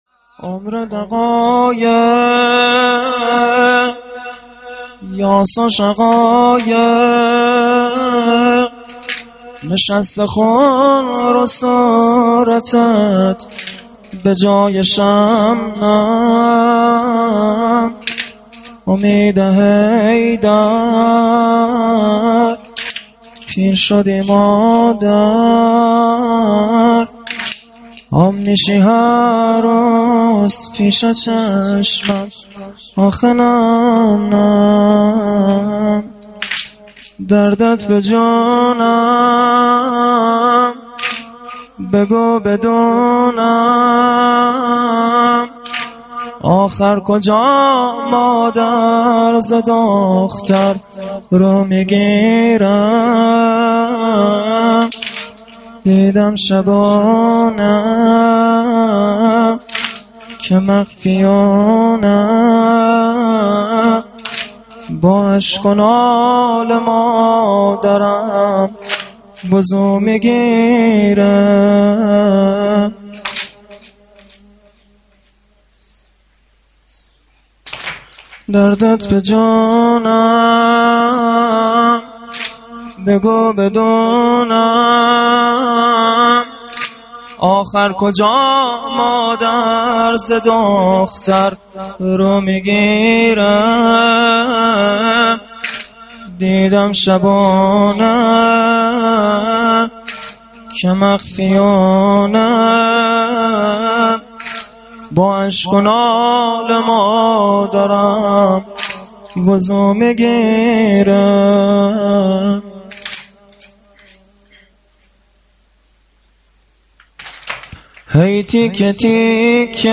مراسم دهه اول فاطمیه